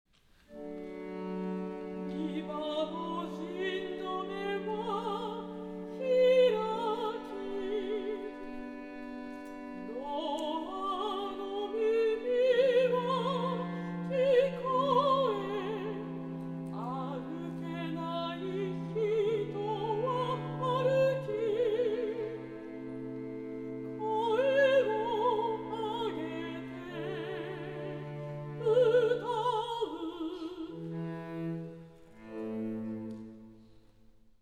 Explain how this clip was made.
Kashiwa Concert Hall